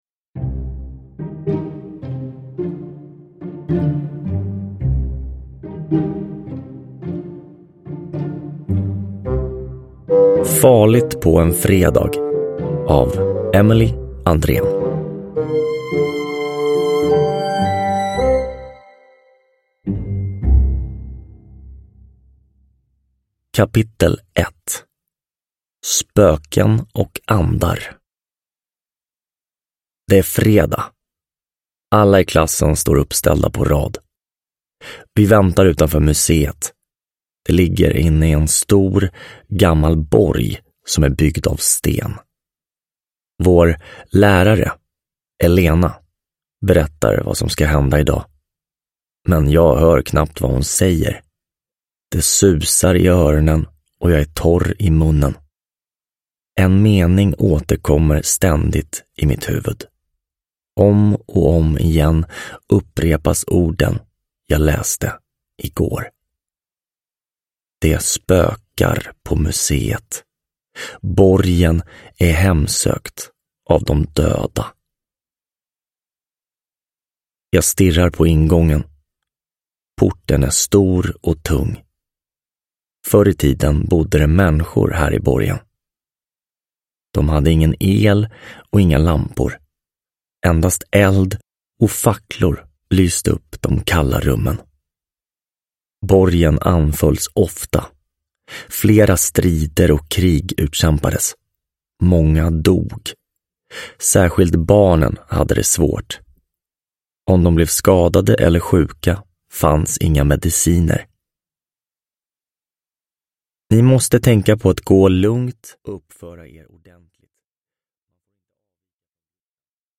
Farligt på en fredag – Ljudbok – Laddas ner